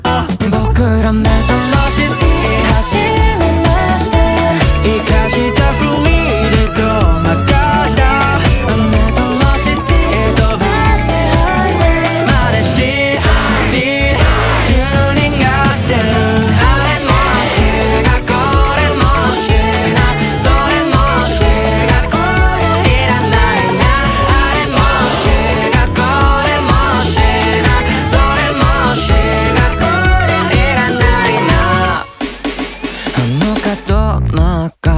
Жанр: Поп / J-pop